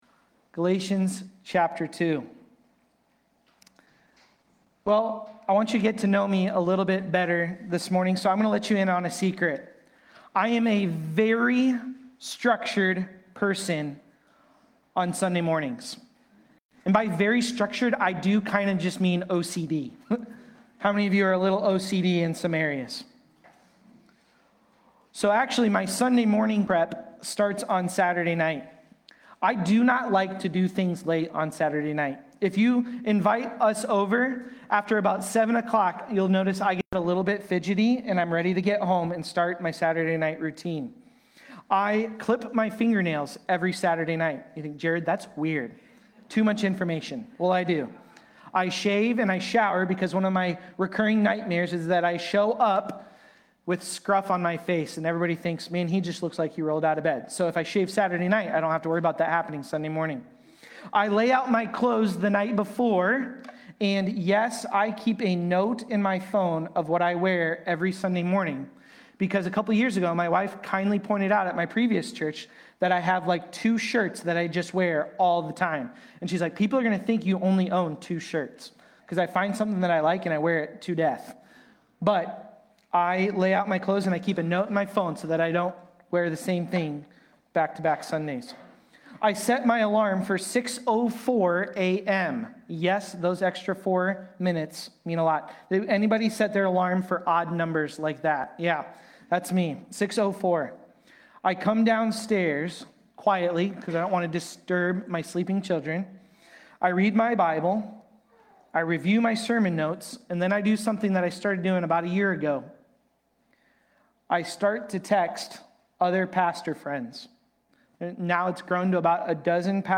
Galatians-2.1-10-Sermon-Audio.mp3